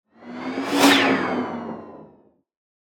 menu-edit-click.ogg